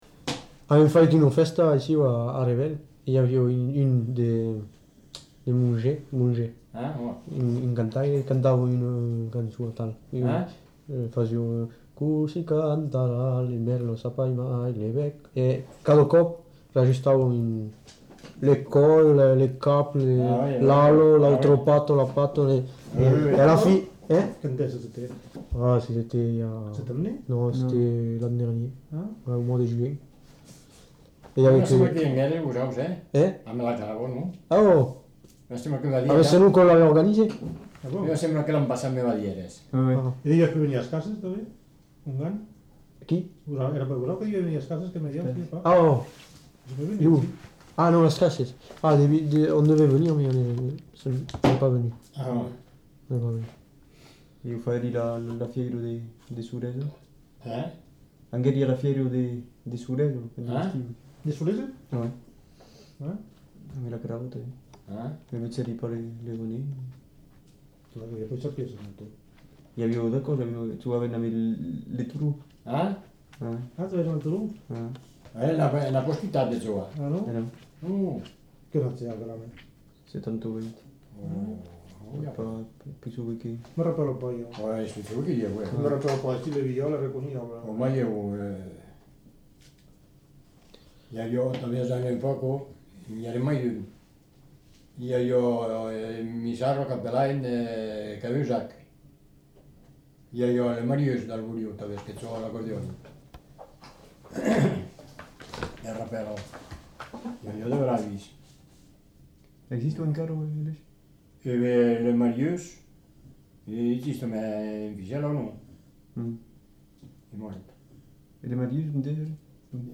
Aire culturelle : Lauragais
Lieu : La Pomarède
Genre : témoignage thématique